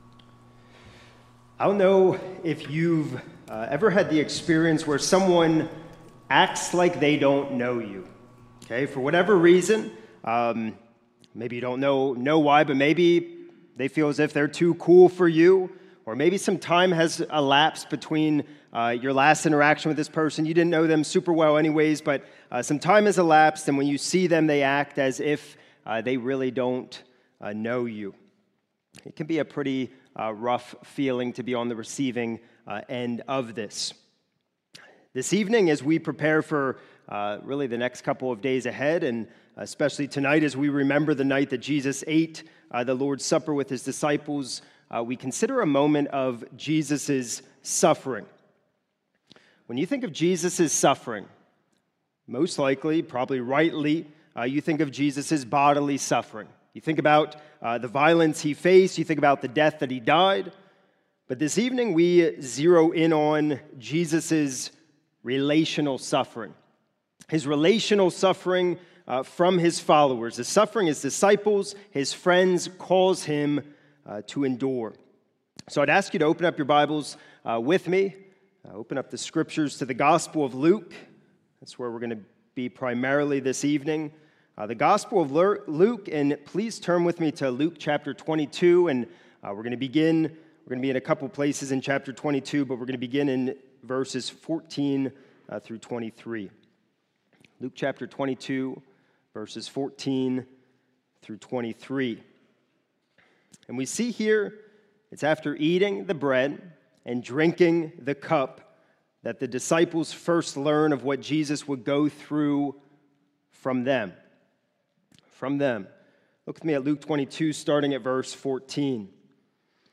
This is a sermon recorded at the Lebanon Bible Fellowship Church in Lebanon, PA during the Maundy Thursday evening communion worship service on 4/2/2026 titled